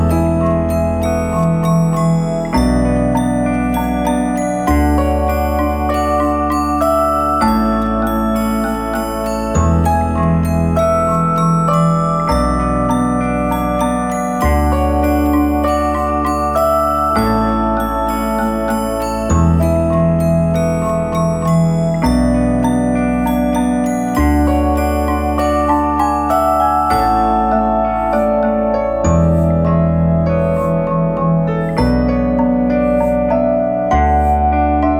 # Lullabies